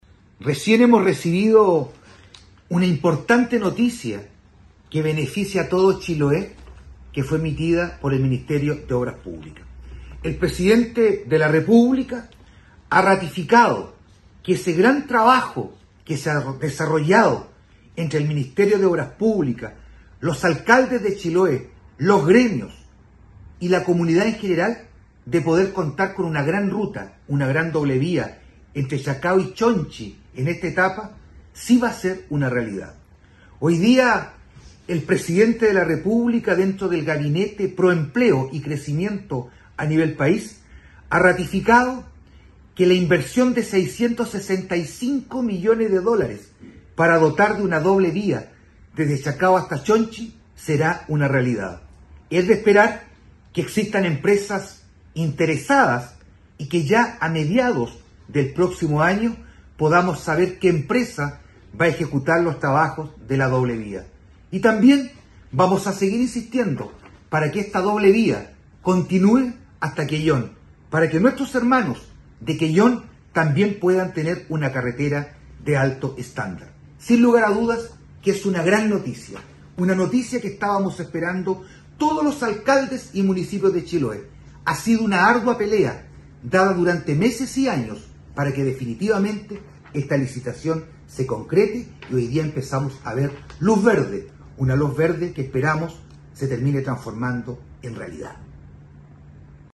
ALCALDE-VERA-RUTA-5.mp3